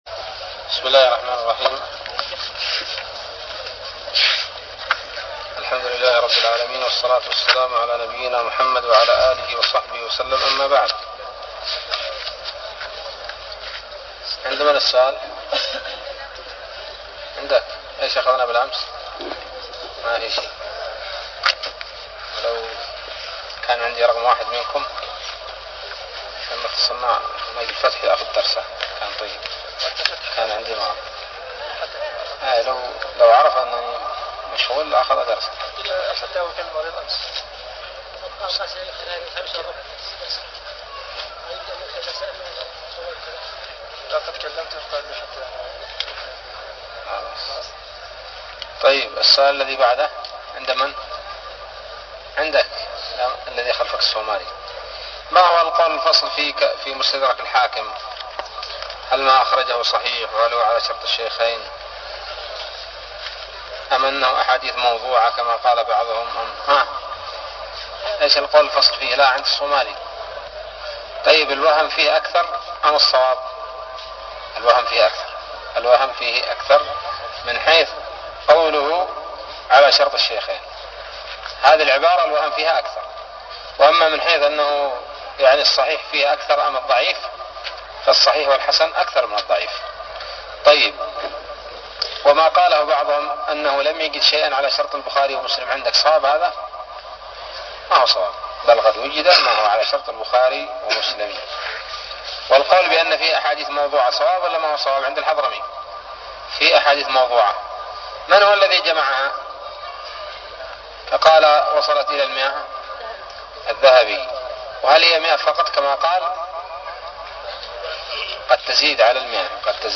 الدرس السابع من الباعث الحثيث